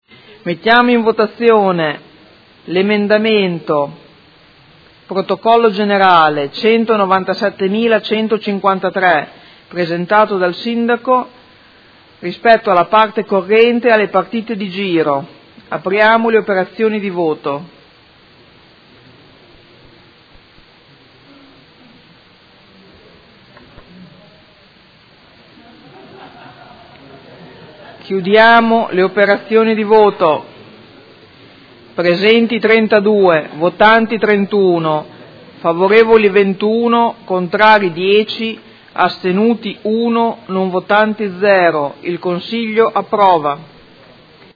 Seduta del 20/12/2018. Mette ai voti emendamento Prot. Gen. 197153